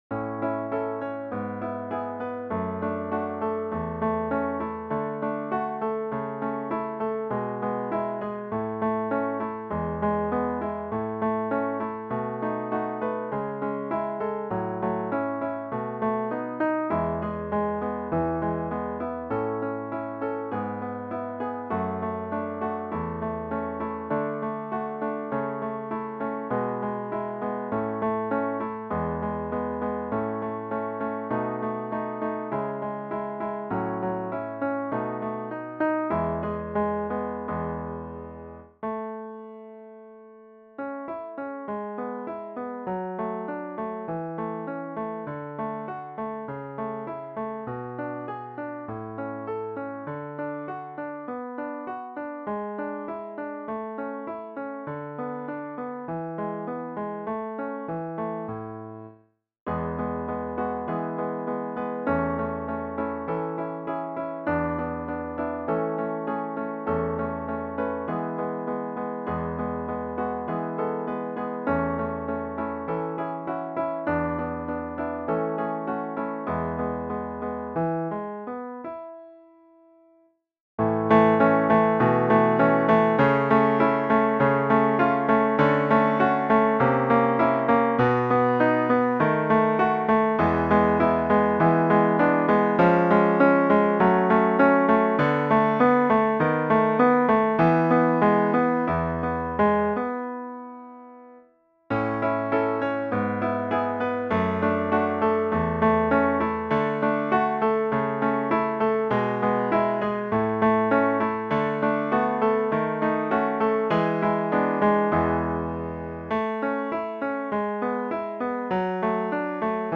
arranged for three guitars and soloist
arranged for guitar trio and solo guitar
The challenge is playing in three sharps.